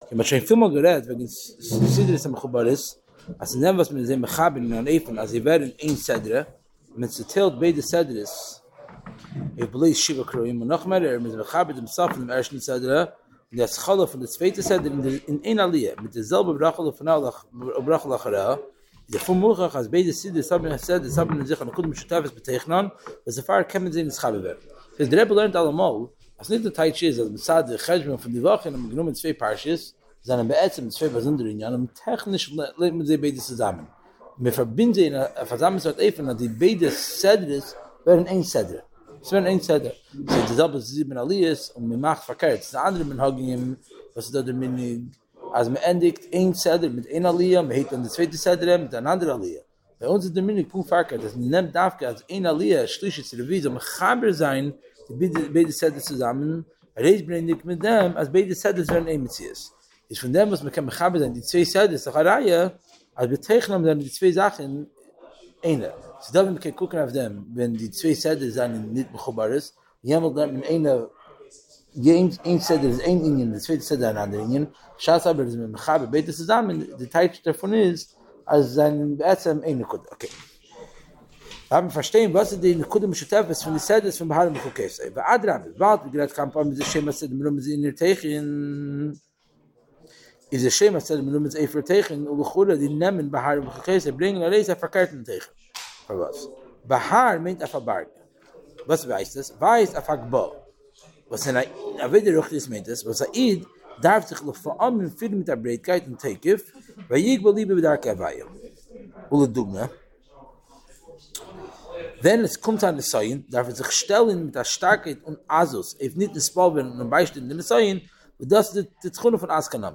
נמסר בבית מנחם - בורו פארק